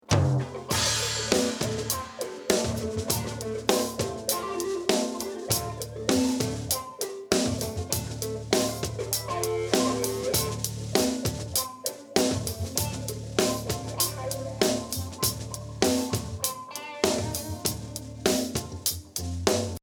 je suis en train de restaurer un vieux enregistrement live que j'avais fait lors d'un concert de mon groupe...
faute de moyens toute la batterie est enregistré sur une piste stéréo (puis les guitares pareils puis la basse et le chant en mono... le tout donc en 6 pistes)
quand on écoute la batterie seule ça va encore
==>> extrait-1 (on entend les autres instrus par les OH de la batterie)... mais alors dès qu'on mixe les autres instruments (guit et basse) la caisse claire disparait presque